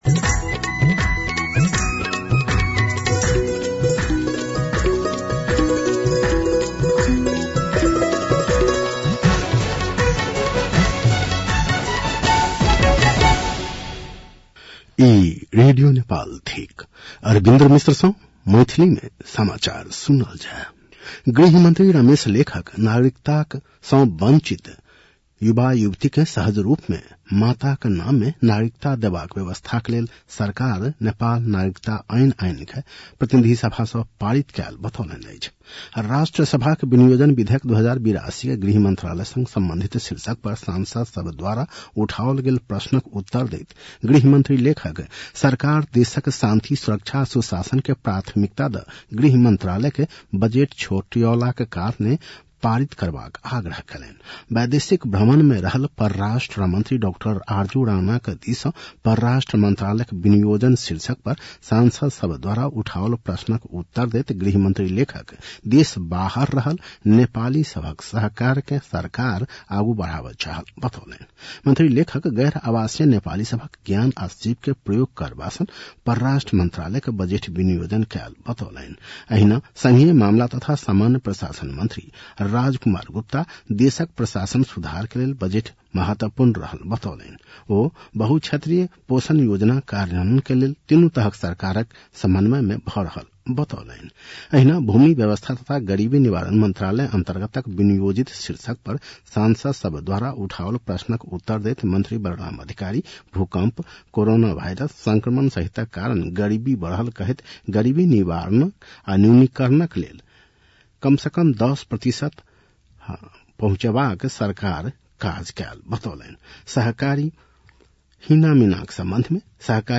मैथिली भाषामा समाचार : १७ असार , २०८२